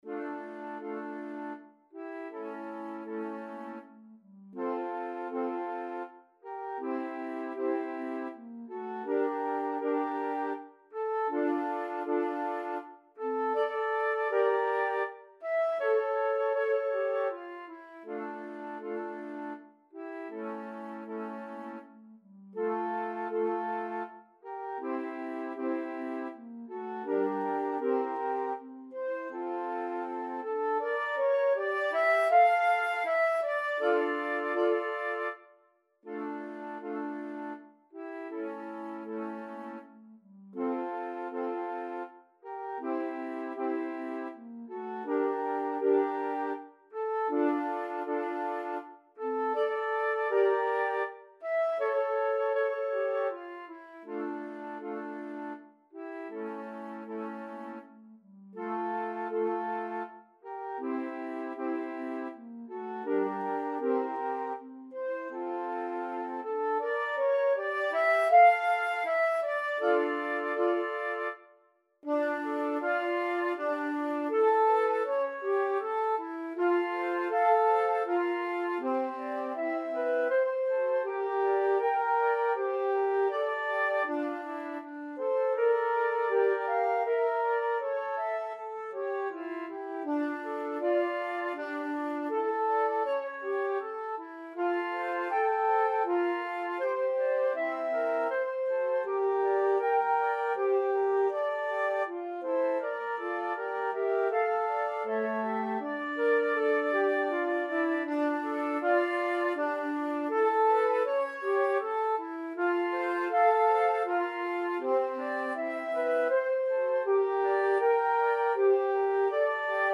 four flutes